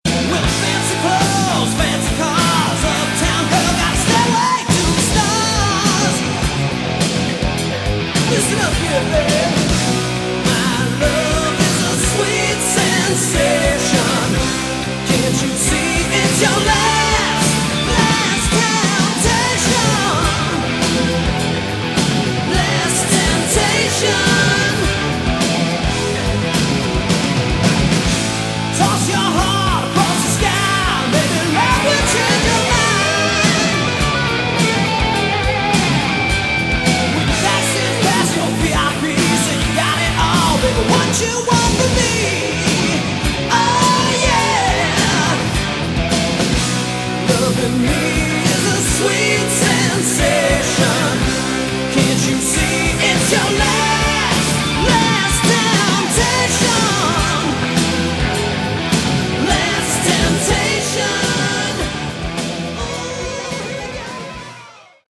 Category: Hard Rock
Vocals
All Guitars
Bass
Keyboards
Drums & Backing Vocals